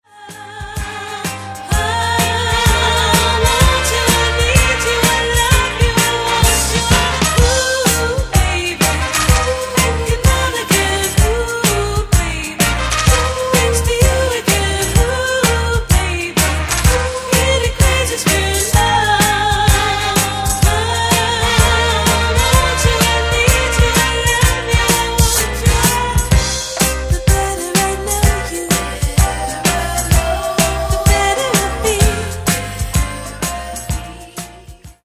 Remastered High Definition
Genere:   Disco